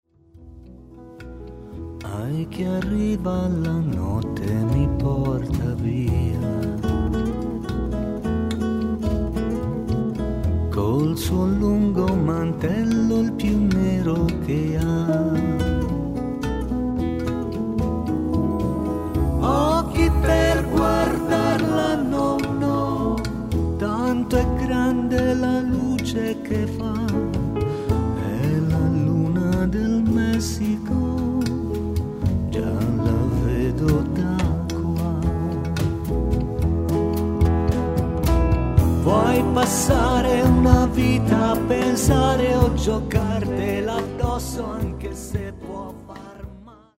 violino
seconda chitarra